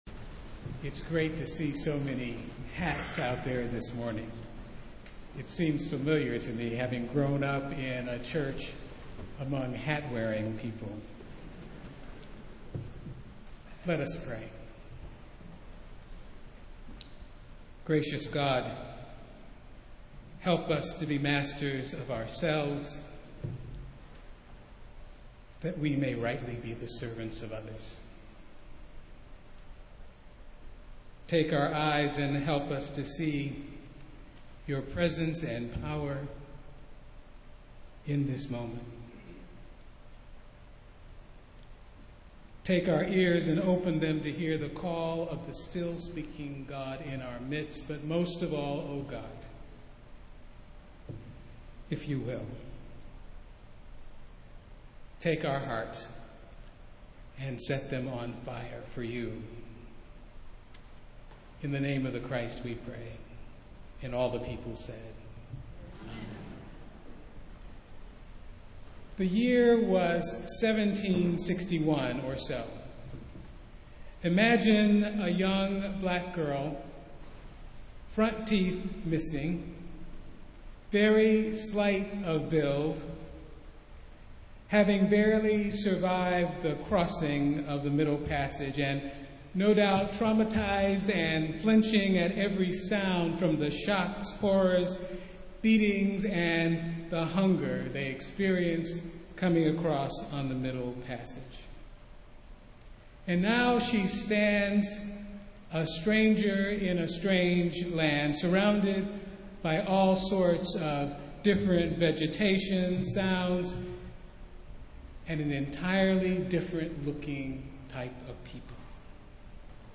Festival Worship - Hat Sunday